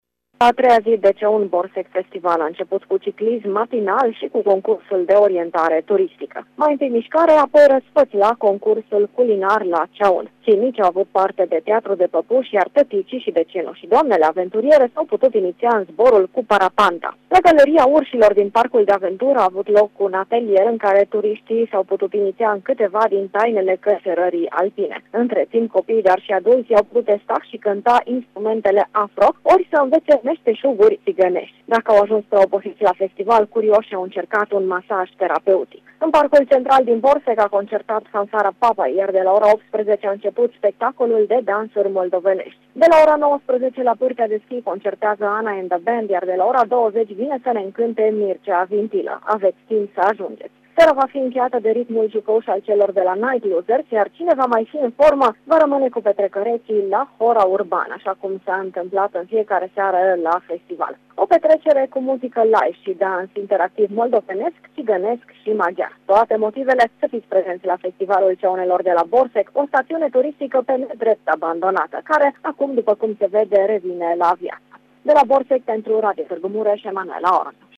Care este atmosfera de la Borsec în ultima zi de festival